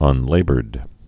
(ŭn-lābərd)